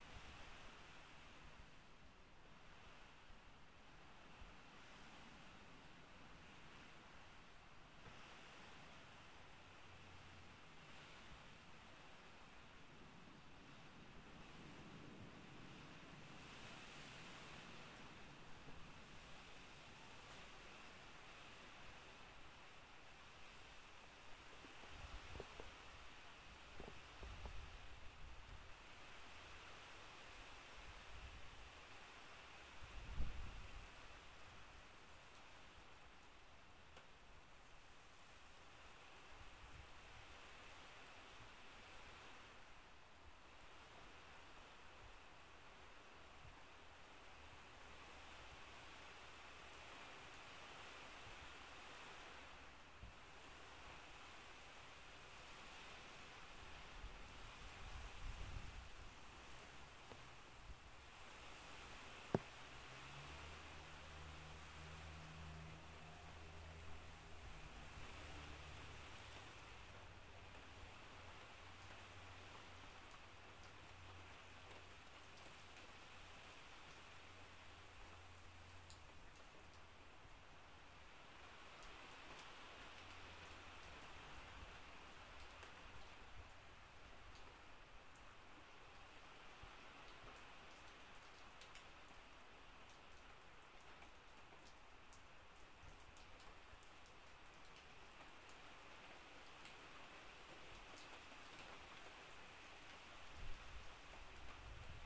暴雨
描述：暴雨席卷了我的门廊。有一点风声。
Tag: 户外 多风 西北部 湿 现场录音 大气 城市